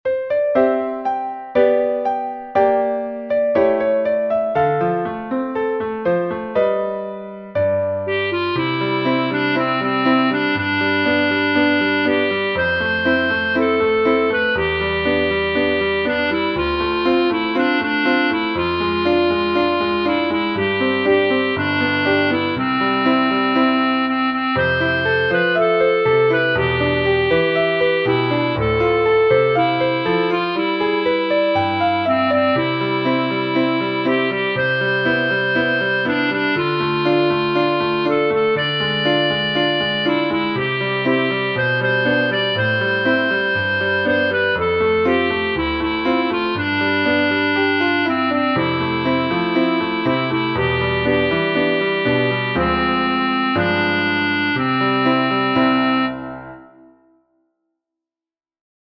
※歌声は入っておりません。 紫雲寺小学校校歌音源 （mp3 920.8 KB） 令和4年度 紫雲寺省、米子小、藤塚小の統合に向けて新しい協議会を設立しました。